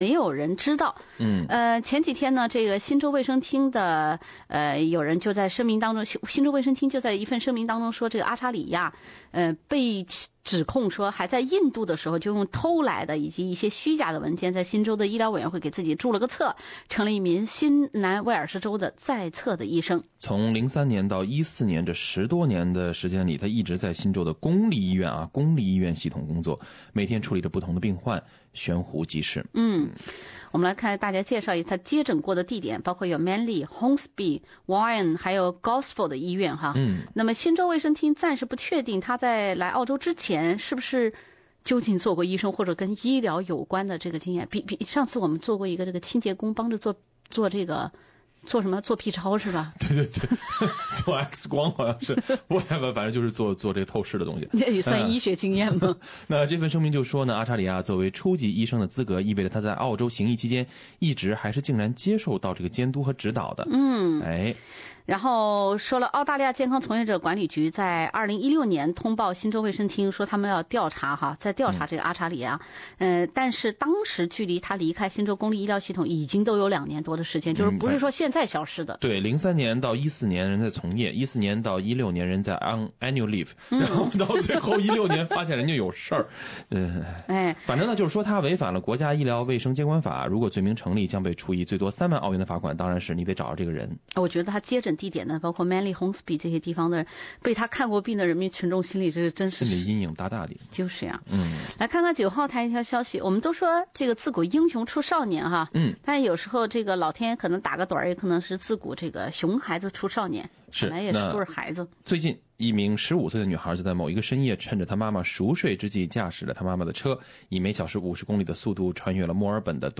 另类轻松的播报方式，深入浅出的辛辣点评；包罗万象的最新资讯；倾听全球微声音。